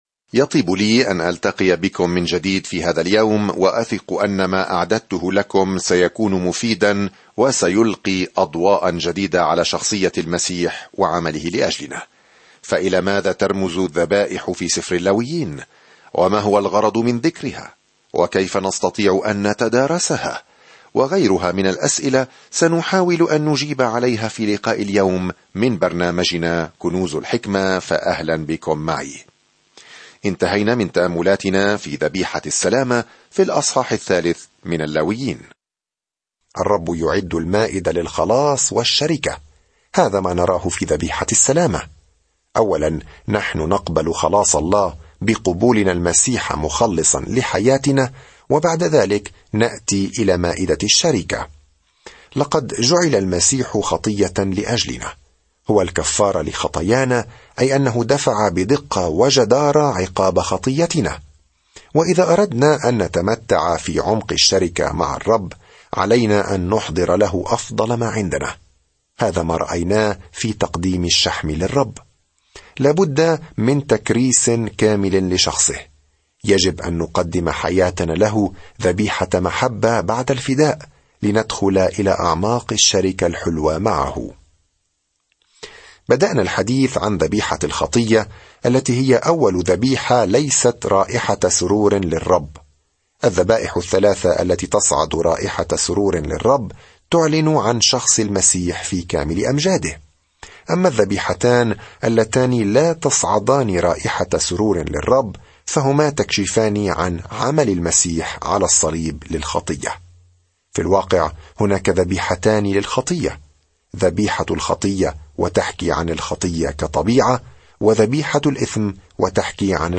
سافر يوميًا عبر سفر اللاويين وأنت تستمع إلى الدراسة الصوتية وتقرأ آيات مختارة من كلمة الله.